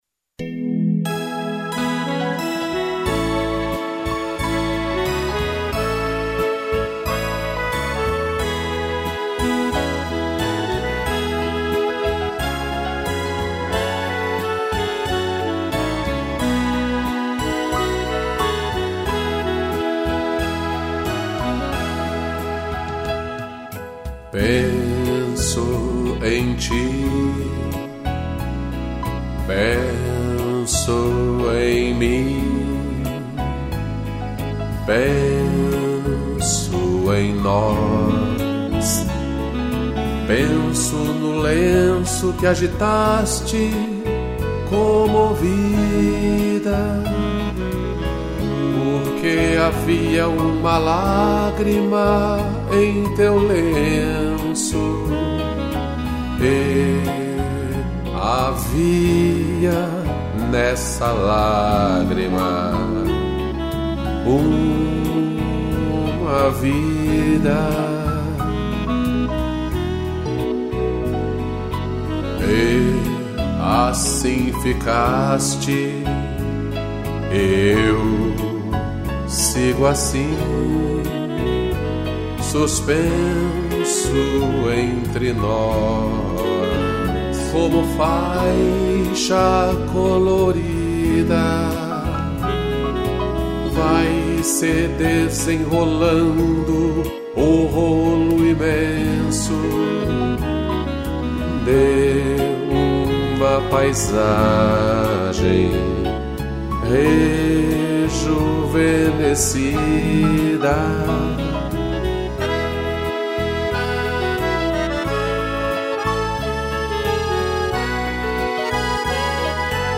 voz
piano e sax